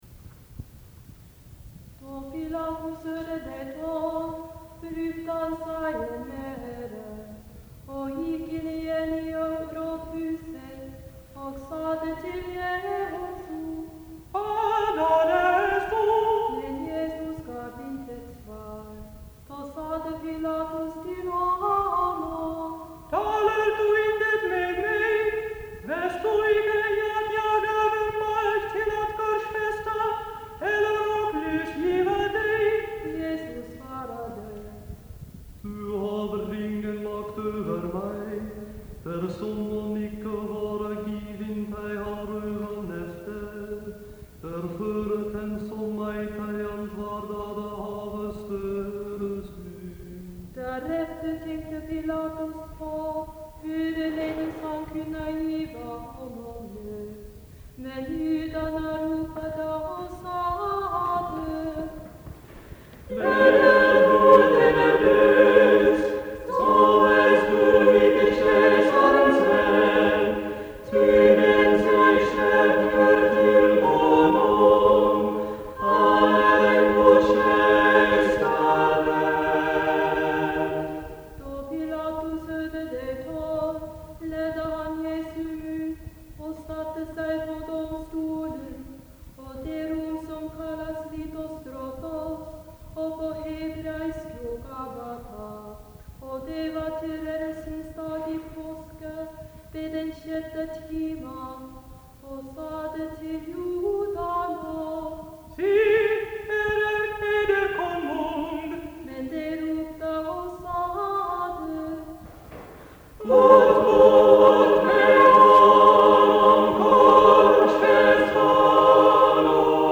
Vocal Ensemble